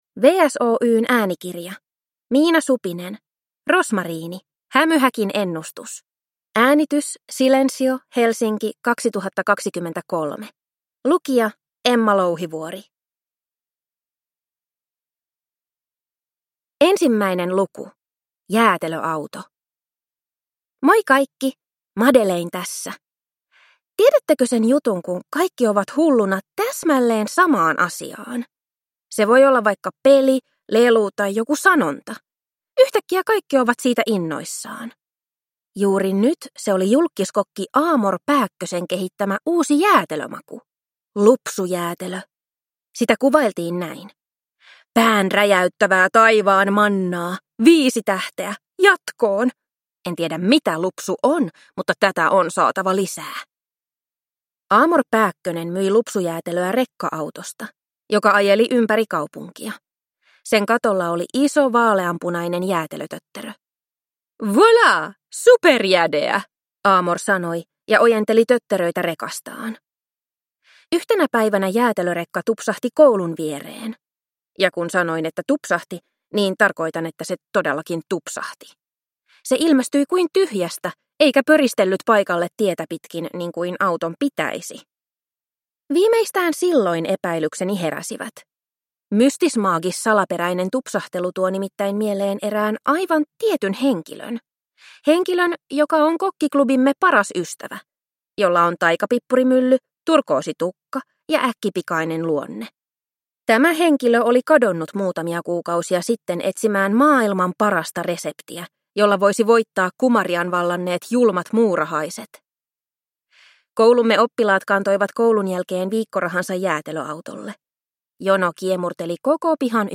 Rosmariini - Hämyhäkin ennustus – Ljudbok